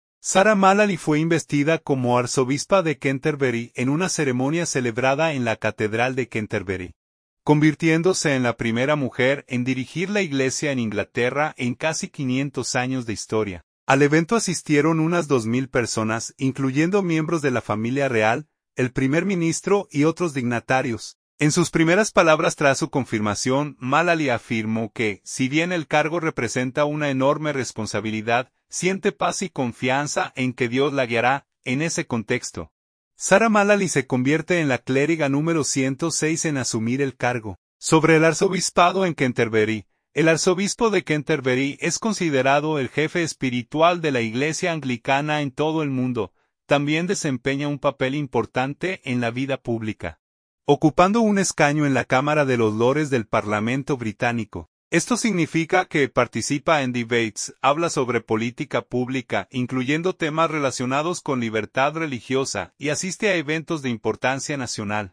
Sarah Mullally fue investida como arzobispa de Canterbury en una ceremonia celebrada en la Catedral de Canterbury, convirtiéndose en la primera mujer en dirigir la Iglesia en Inglaterra en casi 500 años de historia.
Al evento asistieron unas 2.000 personas incluyendo miembros de la familia real, el primer ministro y otros dignatarios.